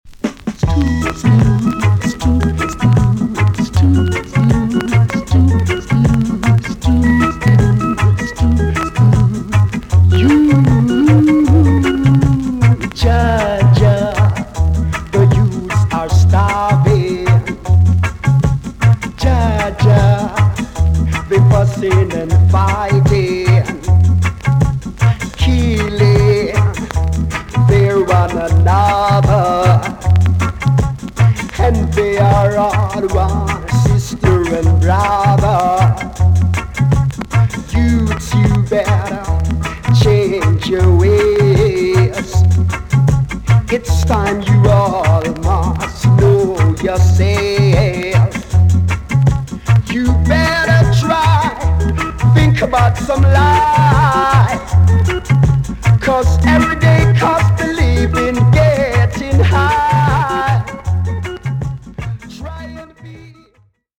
VG+ 少し軽いチリノイズがありますが良好です。
NICE ROOTS VOCAL TUNE!!